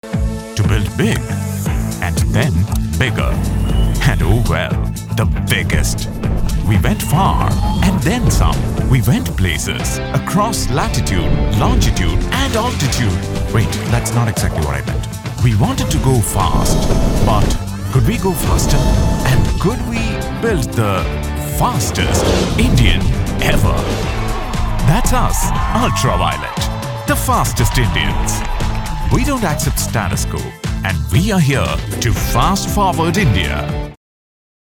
Indian Accent /Neutral English voice with a warm, clear, and versatile tone.
Corporate
0106UltraViolette_Corporate_Launch_VO-English_Indian_Accent.mp3